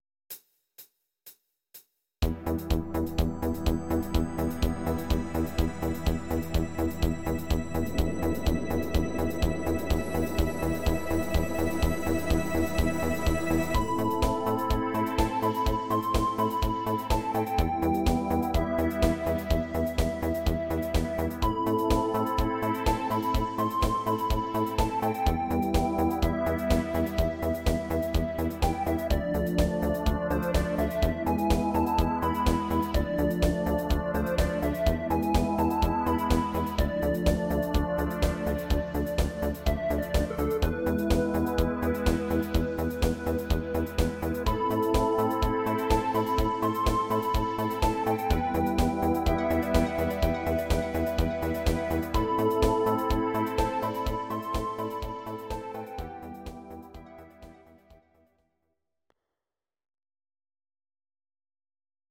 Audio Recordings based on Midi-files
Pop, Instrumental, 1970s